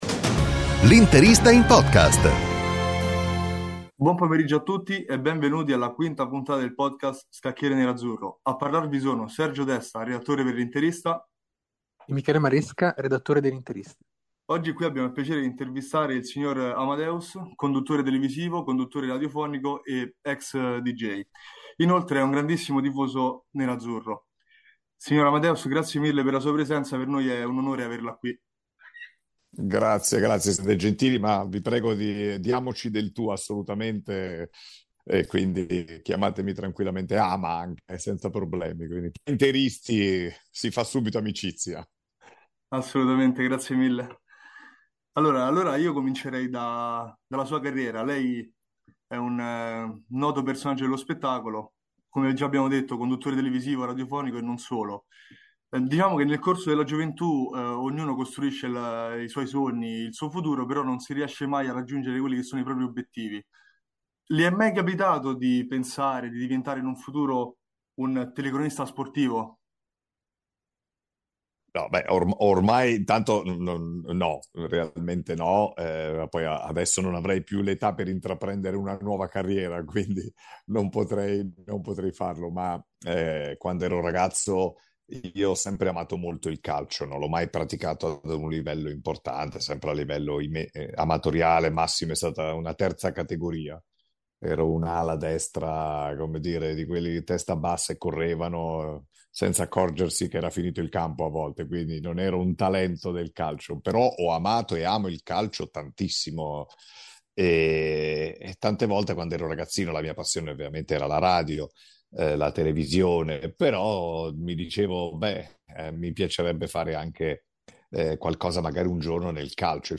Nell'episodio odierno abbiamo il piacere di intervistare Amadeus, conduttore televisivo e radiofonico, nonché grande tifoso dell'Inter. Tanti i temi toccati durante la nostra conversazione: dai suoi sogni legati al mondo del calcio alla forte passione per i colori nerazzurri, passando per un'analisi della situazione al vertice della Serie A. Non è, poi, mancato un riferimento all'importanza dei settori giovanili e a ciò che è necessario per dare una svolta al calcio italiano.